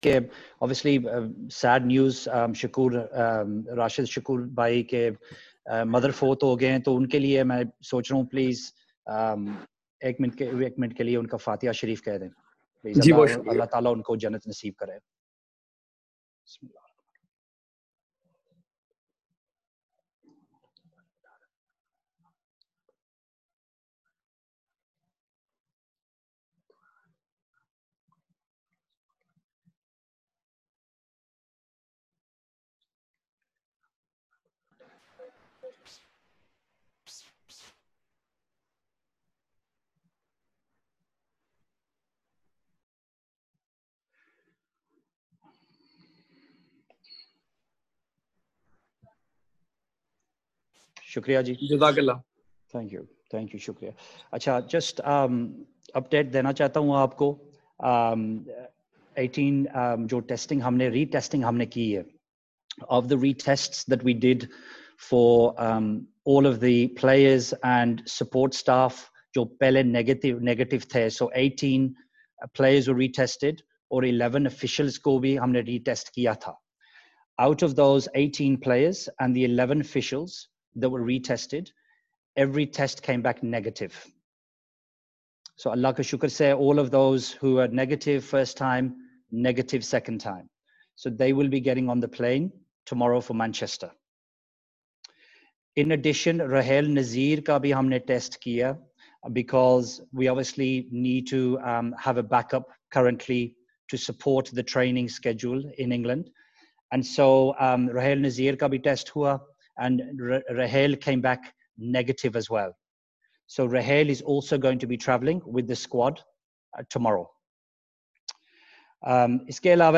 Pakistan Cricket Board Chief Executive Wasim Khan and Test captain Azhar Ali held virtual media conferences on Saturday afternoon.